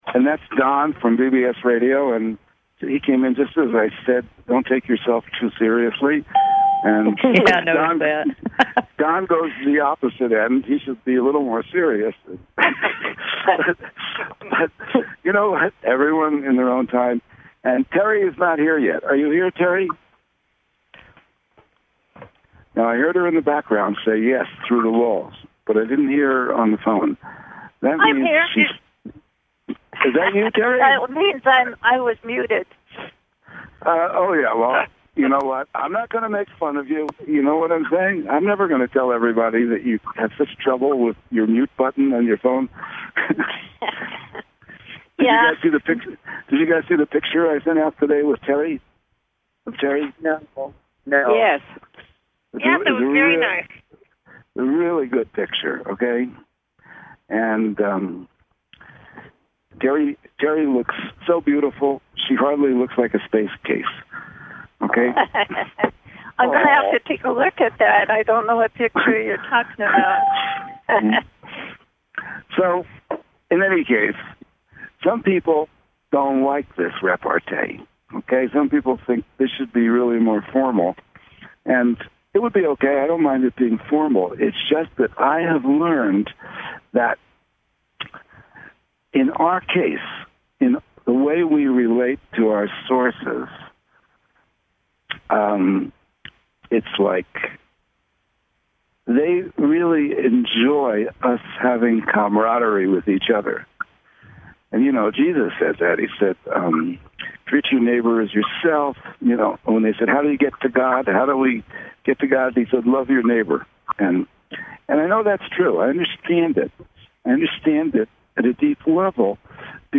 Talk Show Episode, Audio Podcast